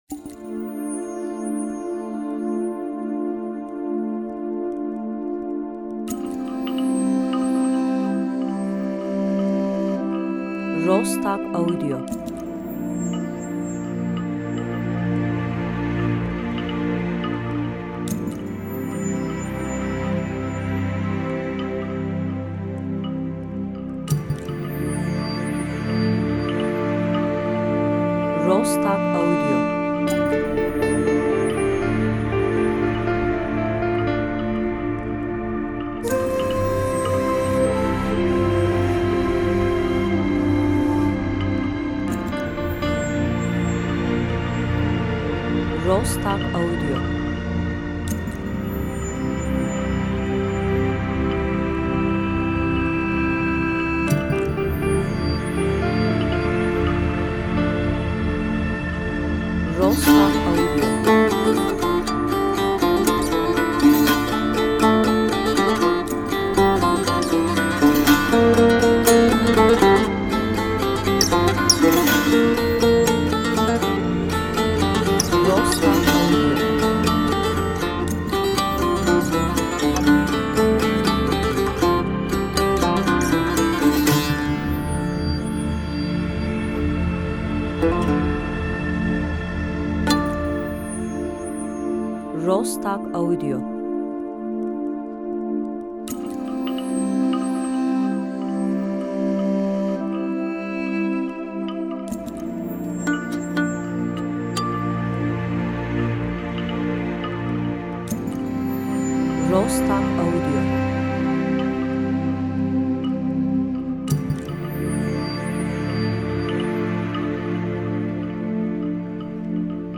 enstrümantal